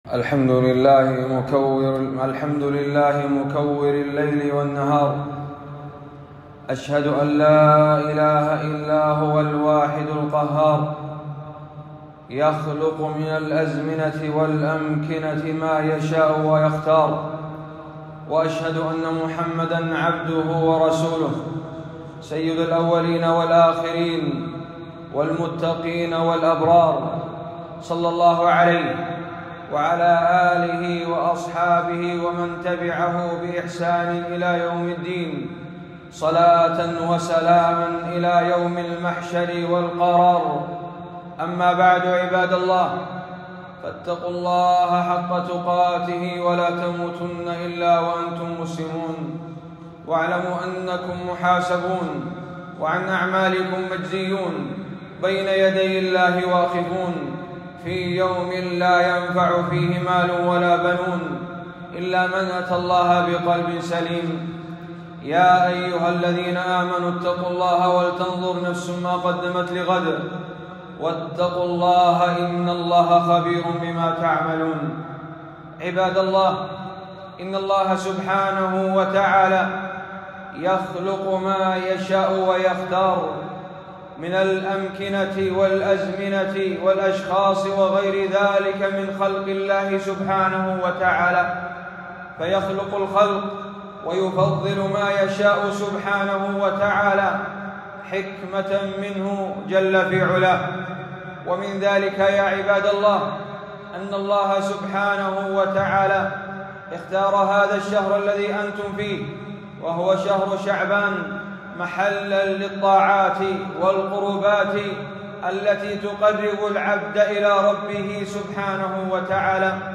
خطبة - من هدي النبي ﷺ والسلف في شعبان